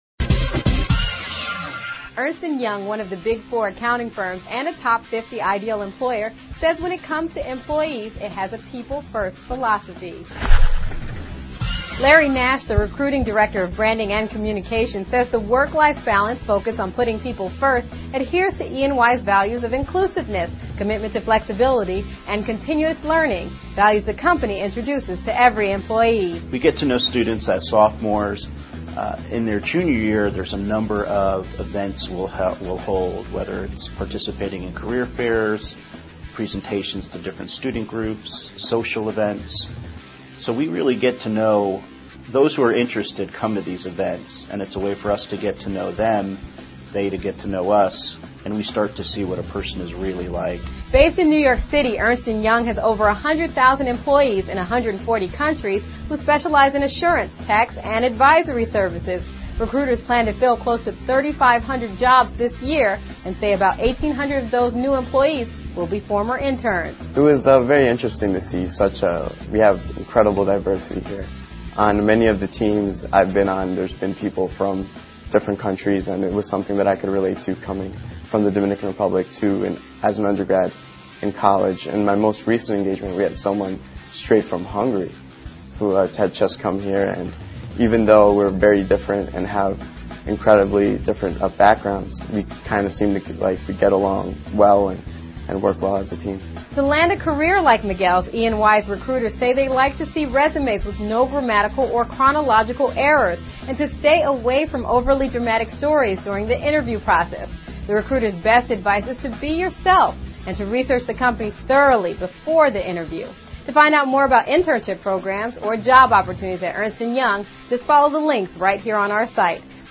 访谈录[Interview]2007-12-14:求职访谈之安永:人为本 听力文件下载—在线英语听力室